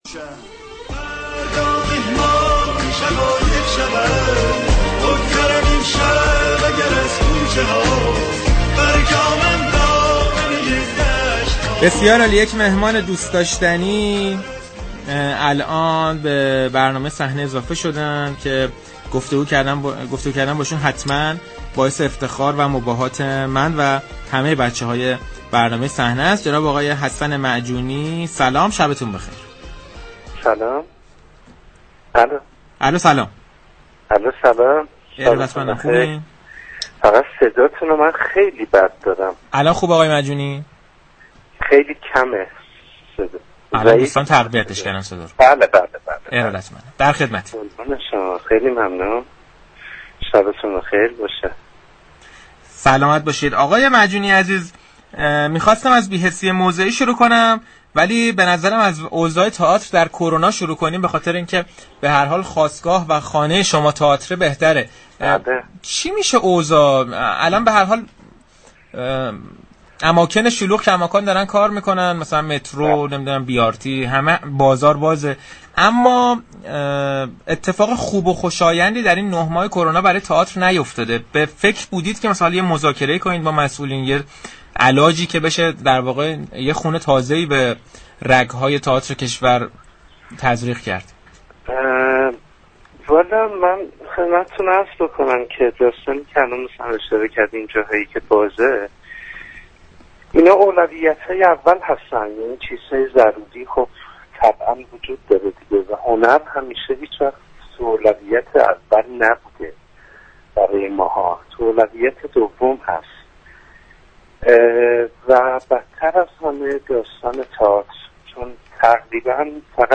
حسن معجونی هنرمند كشورمان در عرصه تئاتر و سینما و تلویزیون در گفتگو با صحنه رادیو تهران درباره شرایط نامناسب تئاتر در شرایط كرونایی گفت: اماكن عمومی مانند بازار، مترو و اتوبوس ها و... جزو اولویت های اول زندگی مردم هستند؛ اما هنر و به خصوص تئاتر در اولویت های پایین تری قرار دارند.